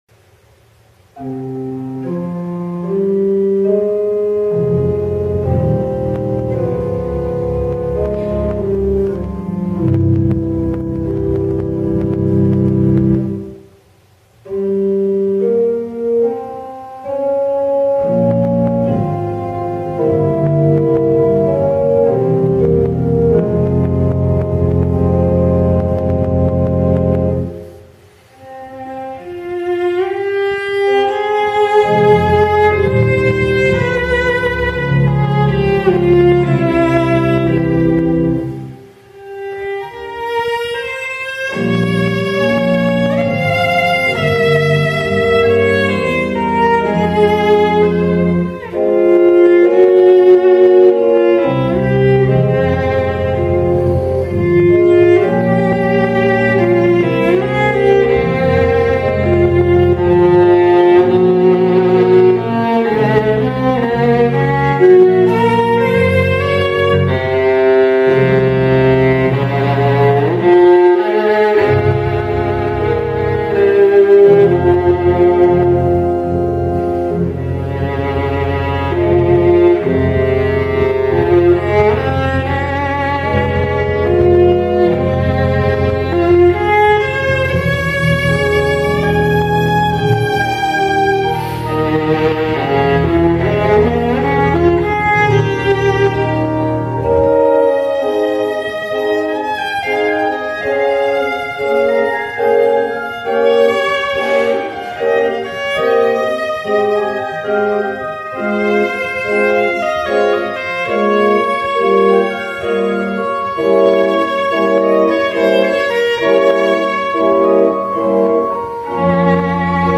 ferdinand_laub_-_adagio_viola.mp3